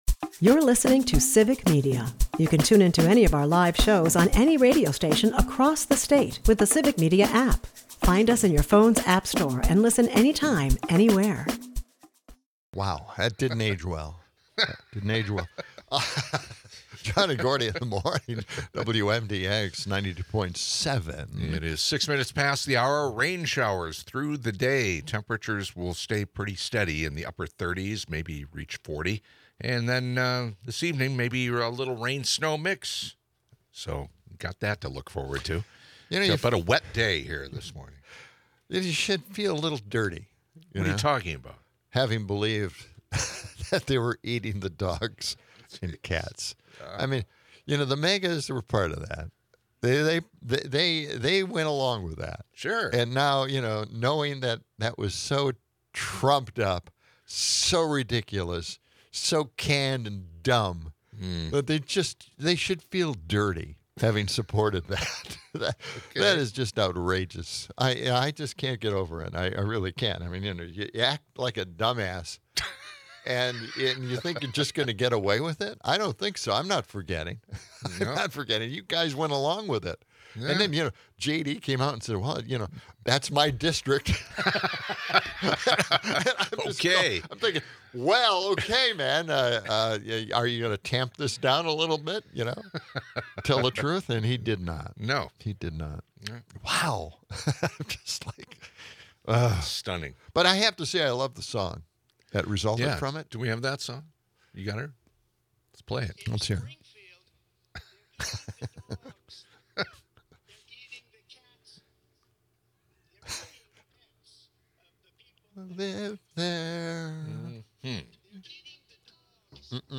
Callers express frustration with recruitment and the current administration's policies, while others highlight the hypocrisy of anti-DEI sentiments.